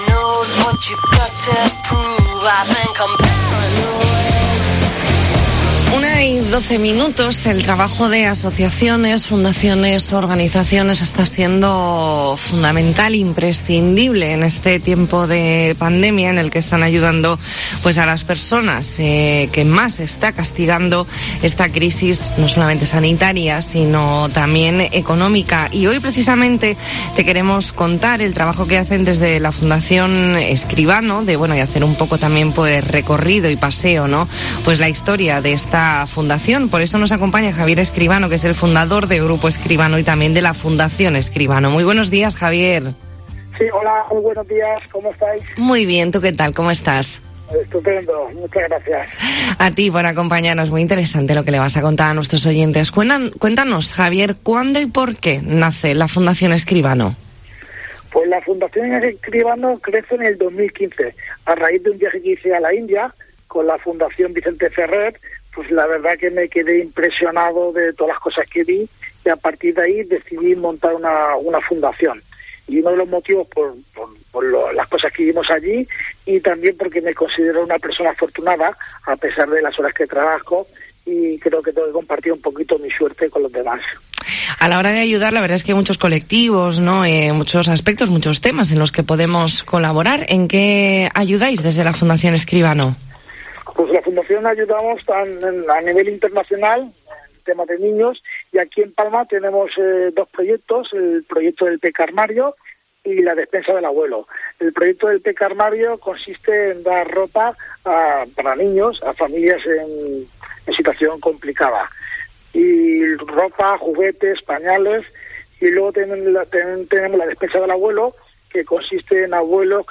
Entrevista en La Mañana en COPE Más Mallorca, martes 20 de abril de 2021.